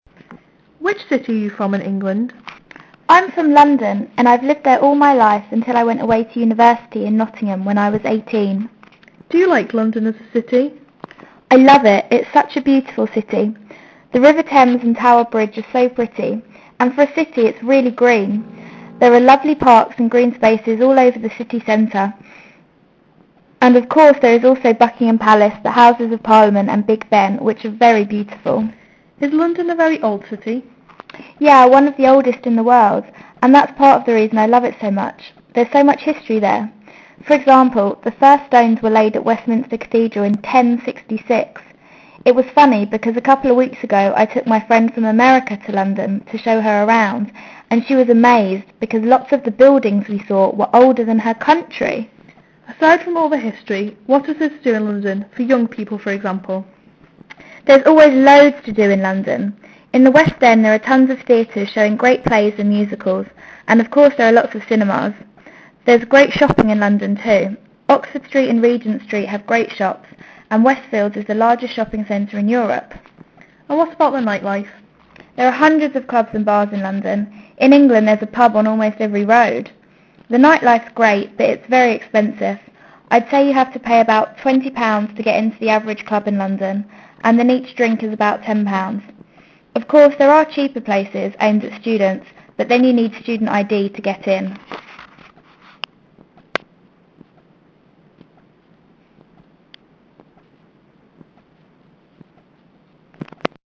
Accent
Anglais